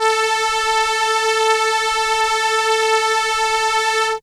/audio/sounds/Extra Packs/musicradar-synth-samples/ARP Solina/Violin/
Violin A4.WAV